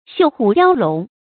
繡虎雕龍 注音： 讀音讀法： 意思解釋： 比喻文章的辭藻華麗。